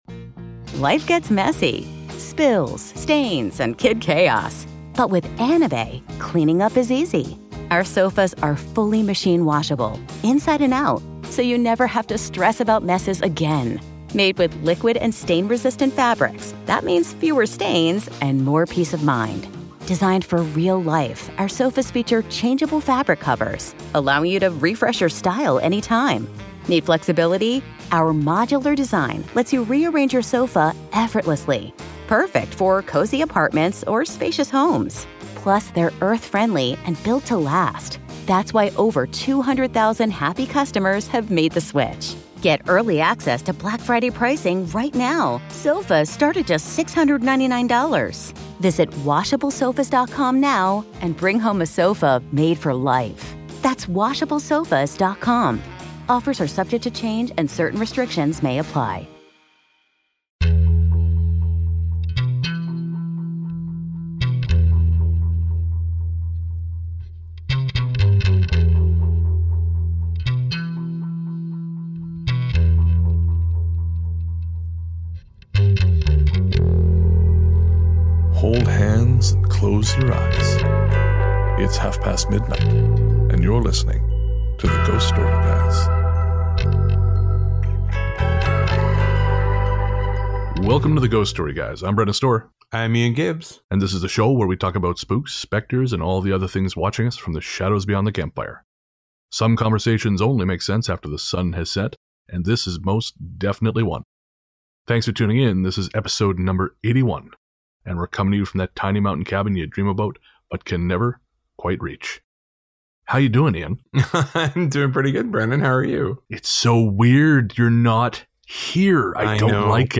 This time around we join Idris Elba in canceling the apocalypse by recording this bad boy from 2 separate locations, a first for the show. We laugh, we cry (not really), and we tell some great goddamn listener stories, in defiance of nature and turducken, the story of which you'll have to listen to find out.